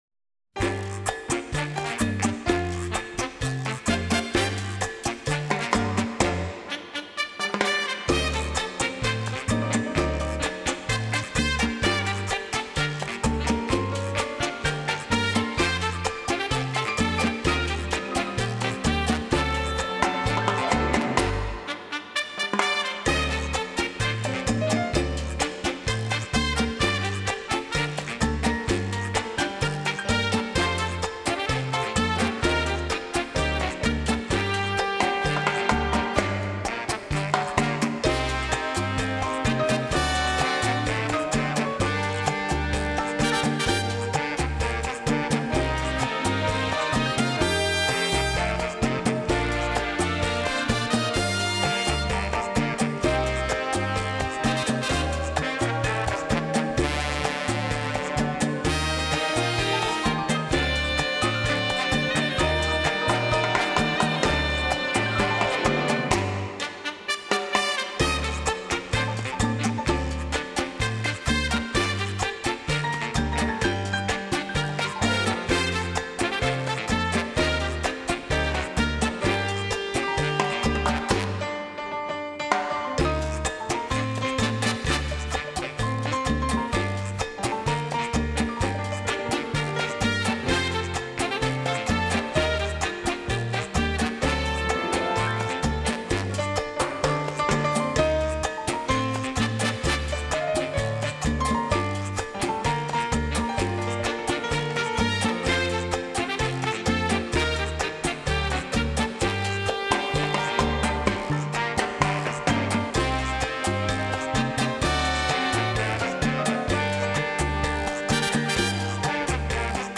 Genre: Easy Listening, Instrumental
Cha cha cha